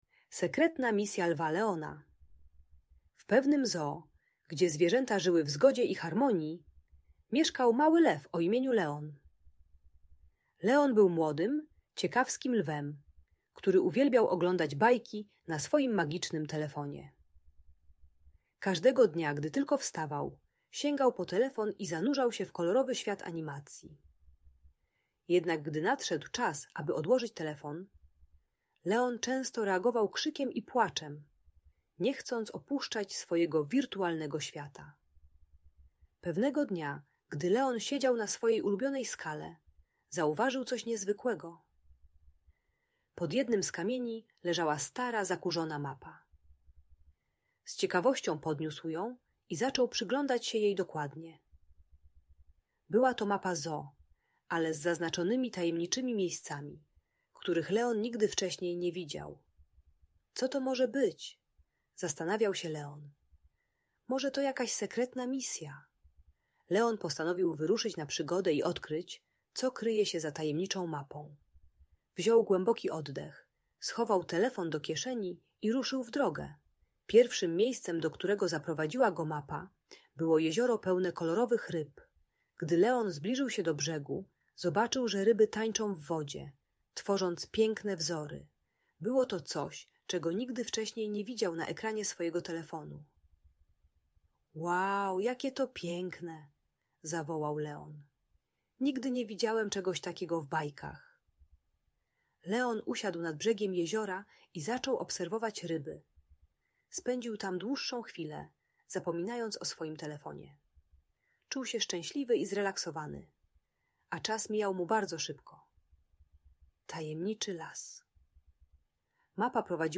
Sekretna Misja Lwa Leona - Audiobajka dla dzieci